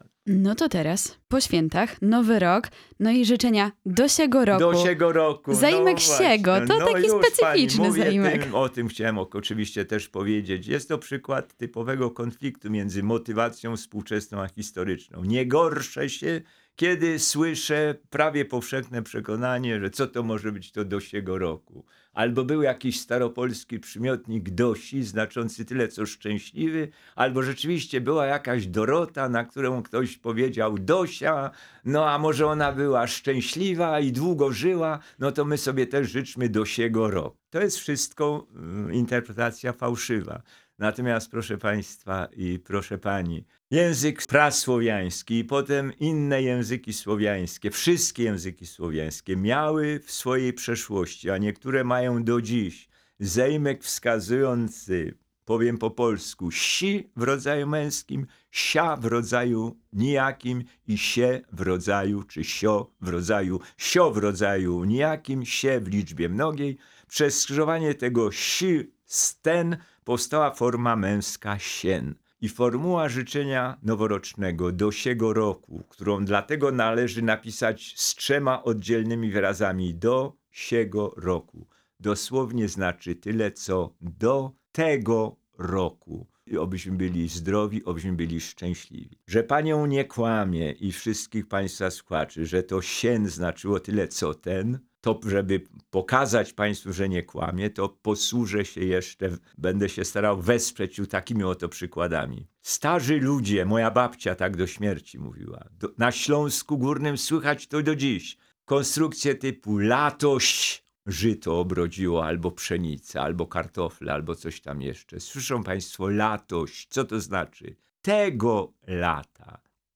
Językoznawca, prof. Jan Miodek odwiedził w pierwszy dzień świąt studio Radia Rodzina. W rozmowie opowiedział o korzeniach terminów związanych z Bożym Narodzeniem, a także o świętach, które pamięta z dzieciństwa.